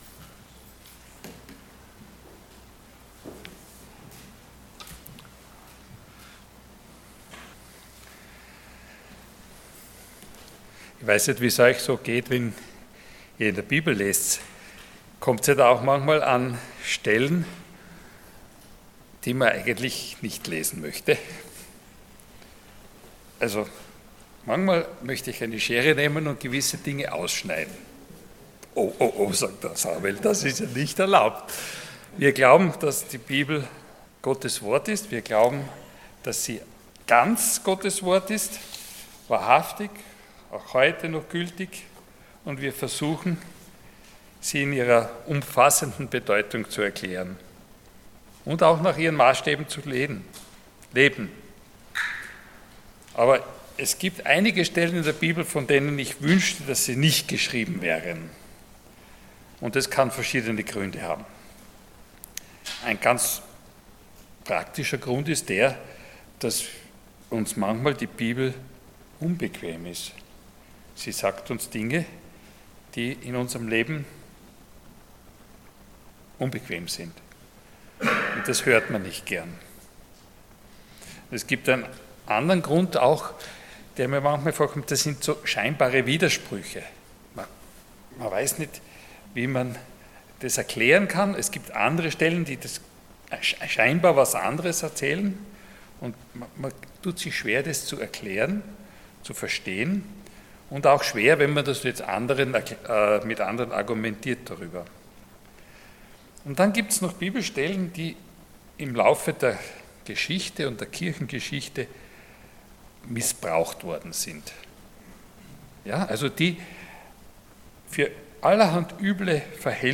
Passage: Römer 13,1-7 Dienstart: Sonntag Morgen